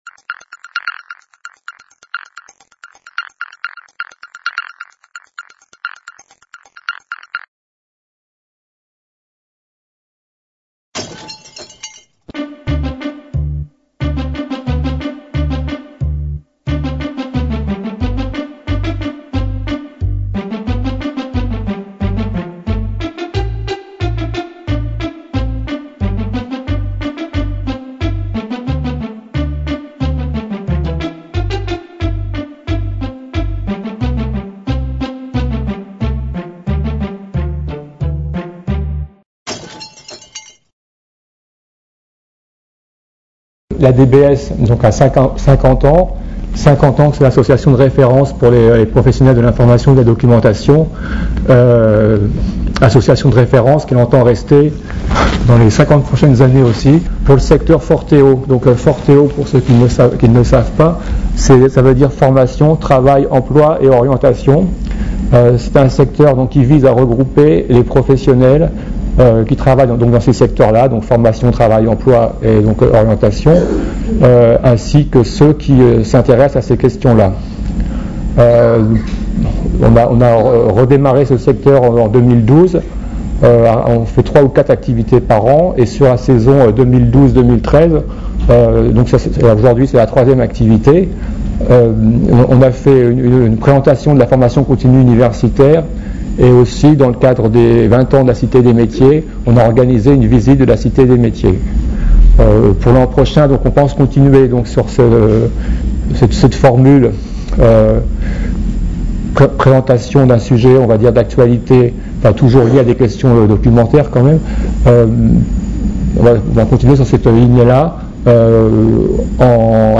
Le secteur FORTEO [Formation, Travail, Emploi, Orientation] de l'ADBS [Association des professionnels de l'information] a organisé une demi-journée d'information sur la formation à distance à destination des professionnels de la formation.